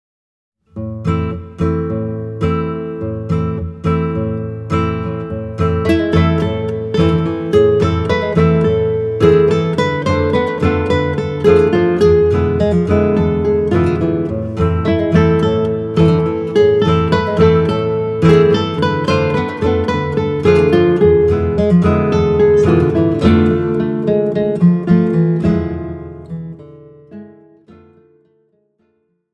Gattung: für drei oder vier Gitarren